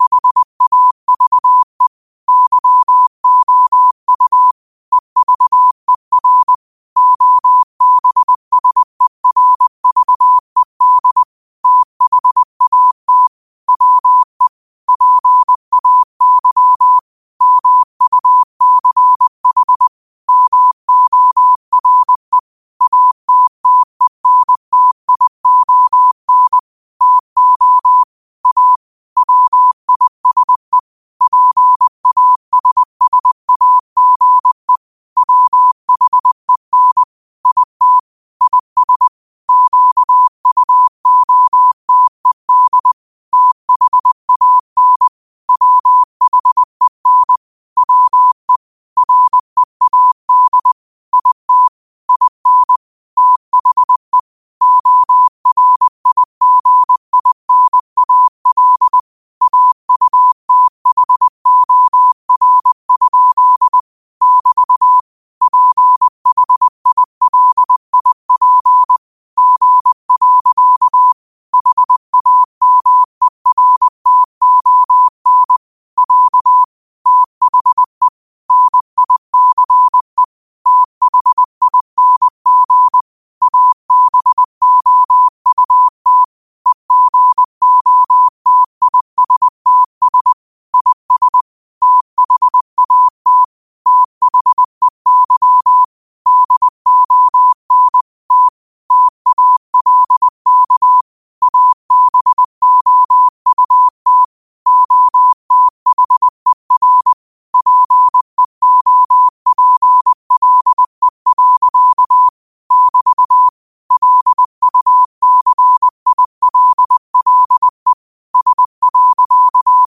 New quotes every day in morse code at 20 Words per minute.